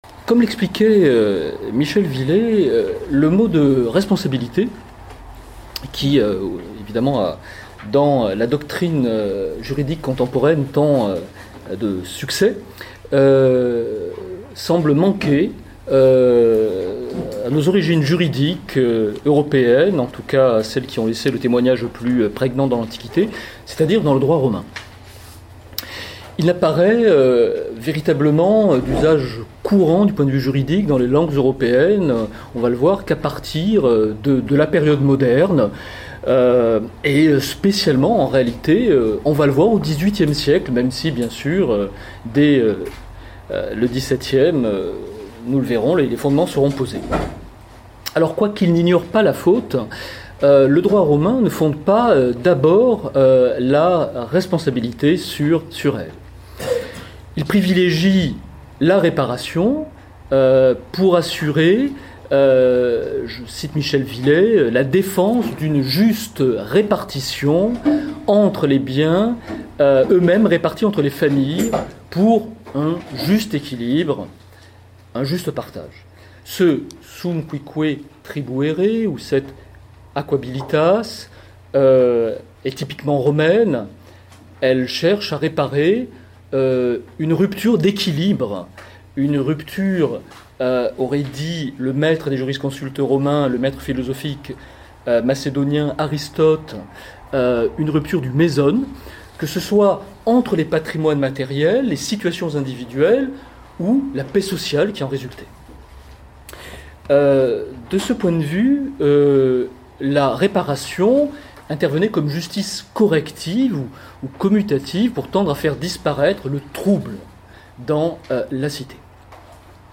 Du 1er au 4 juin dernier se sont déroulées à la Faculté de Droit de Tours, les Journées internationales de la Société d'Histoire du Droit, association scientifique internationale plus que centenaire. Le thème qui avait été proposé par notre Faculté était la Responsabilité.
Près de 180 auditeurs furent présents pour entendre 63 communicants d'une dizaine de nationalités différentes (française, espagnole, italienne, hollandaise, belge, polonaise, allemande, suisse, autrichienne, portugaise, hongroise et bulgare).